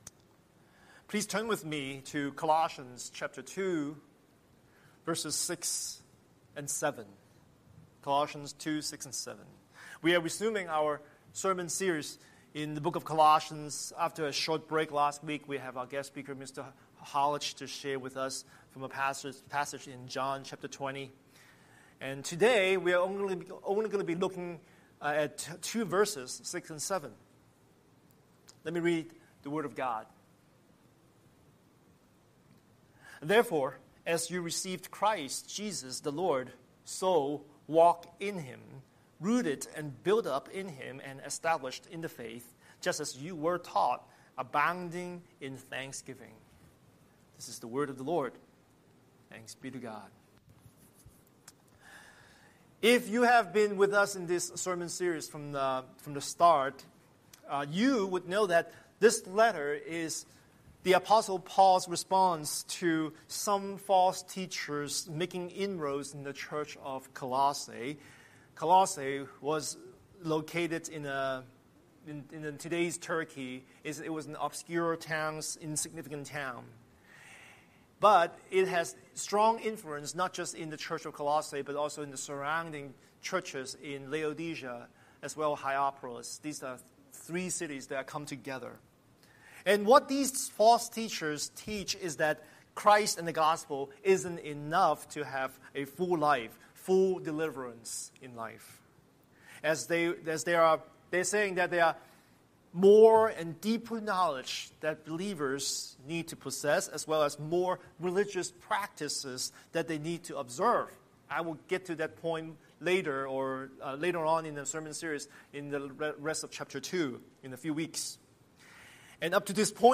Scripture: Colossians 2:6-7 Series: Sunday Sermon